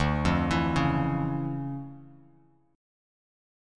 DmM9 chord